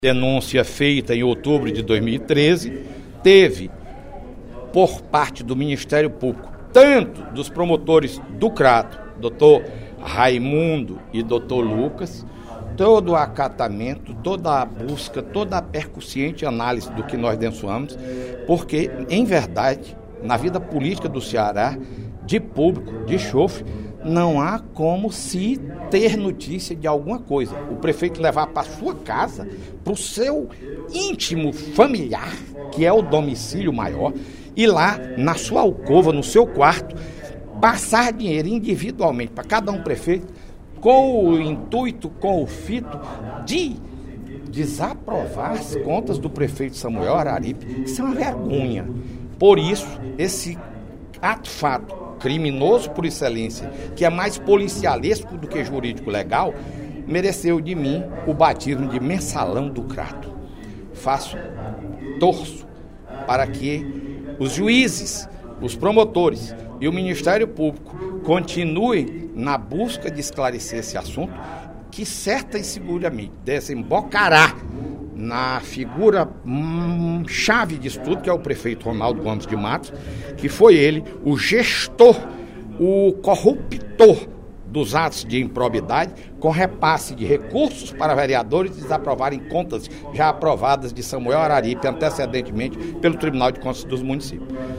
O deputado Fernando Hugo (SDD) criticou, no primeiro expediente da sessão plenária desta quarta-feira (12/03), o arquivamento do caso de corrupção que ele chama de “mensalão do Crato”.